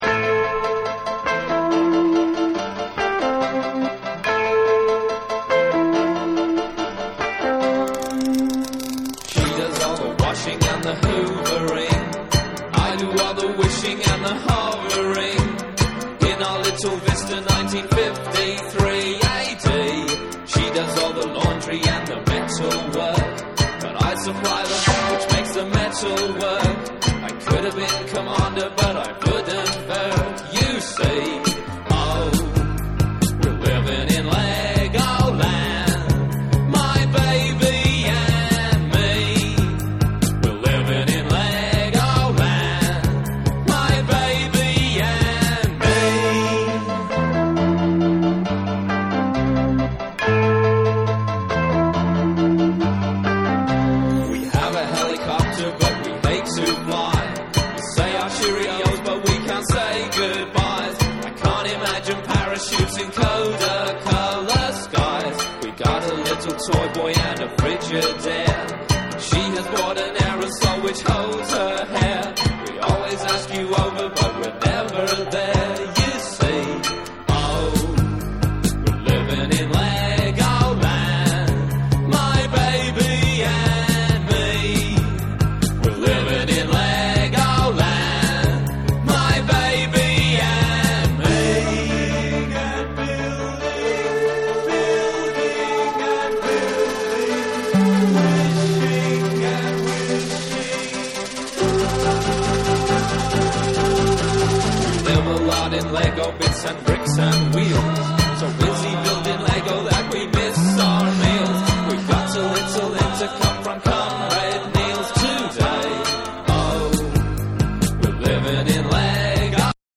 ブリージンなコーラスが印象的なAORナンバー